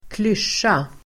Uttal: [²kl'ysj:a]